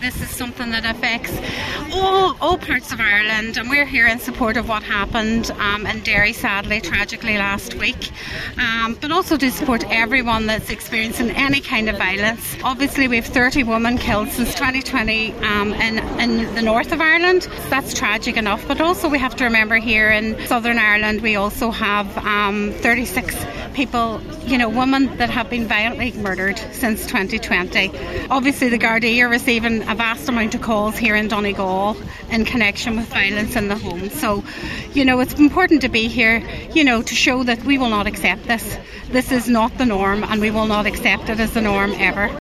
Over 150 people gathered outside the Lifeline Inishowen premises in Carndonagh last night for a candlelit vigil
Cllr Joy Beard told the gathering that domestic violence can never be accepted, and people cannot stay silent…………..